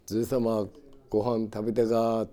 Aizu Dialect Database
Type: Yes/no question
Final intonation: Falling
Location: Showamura/昭和村
Sex: Male